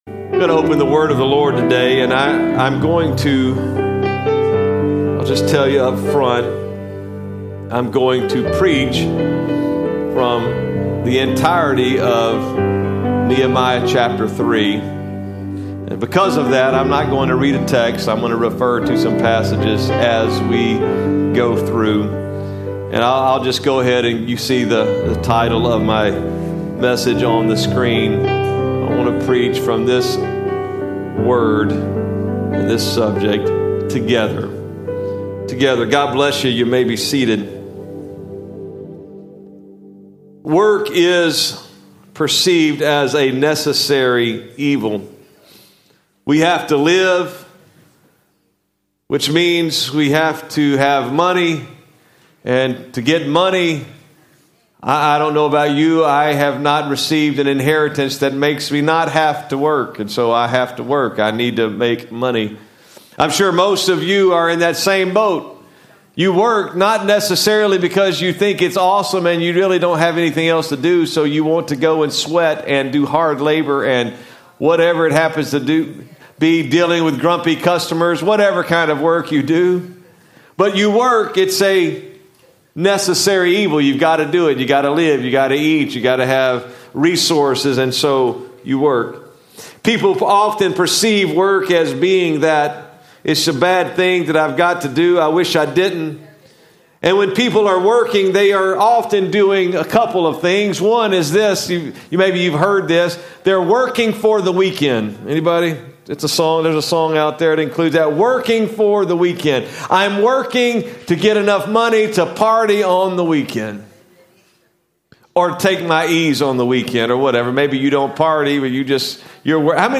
Sermons | Cross Church Kansas City